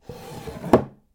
桐タンス空引出し開
op_chest_drawer3.mp3